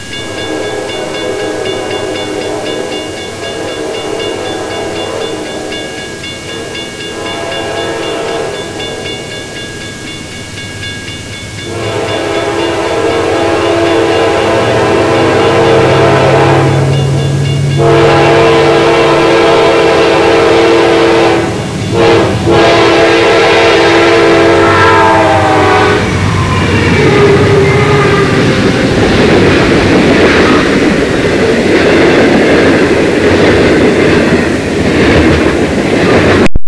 - Raleigh, NC
same crossing east of Raleigh at night. This is the base
NS D9-40CW leading 2 EMD's, great doppler effect.